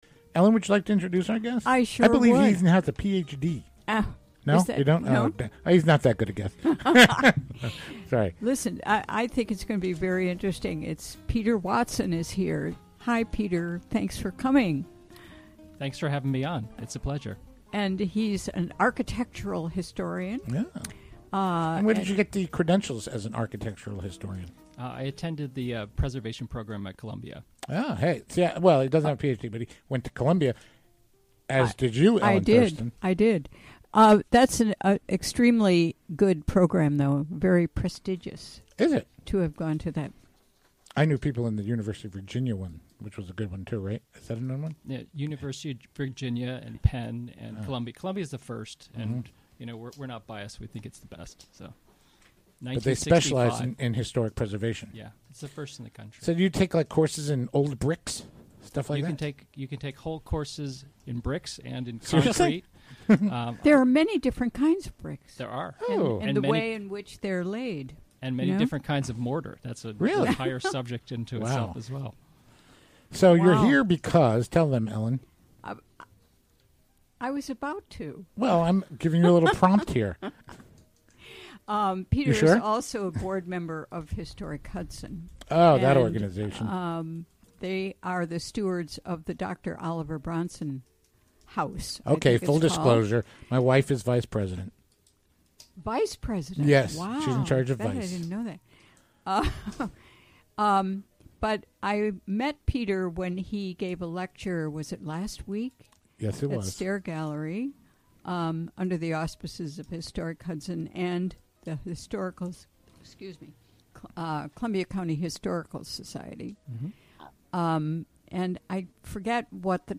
6pm The show features local news, interviews with comm...